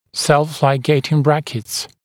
[self-laɪˈgeɪtɪŋ ‘brækɪts][сэлф-лайˈгейтин ‘брэкитс]самолигирующиеся брекеты